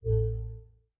caps - Caps is a Windows utility that provides audible notifications when the Caps Lock key is toggled.